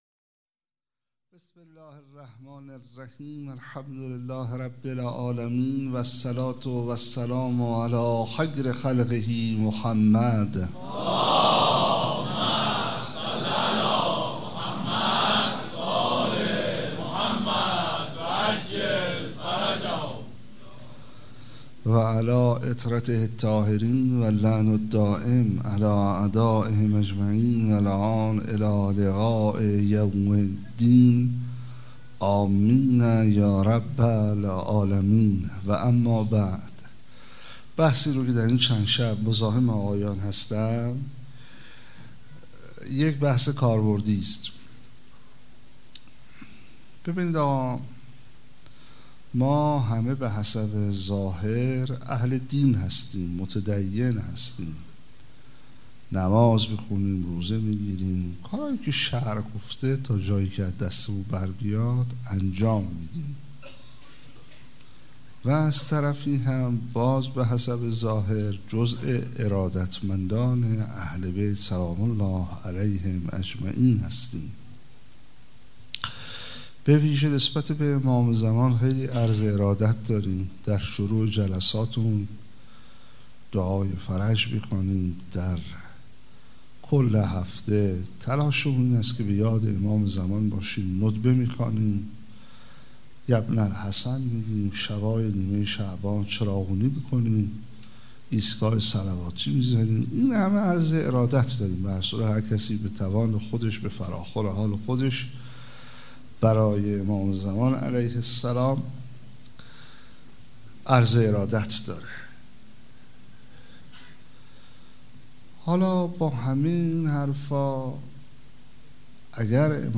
26 بهمن 96 - ریحانه الحسین - سخنرانی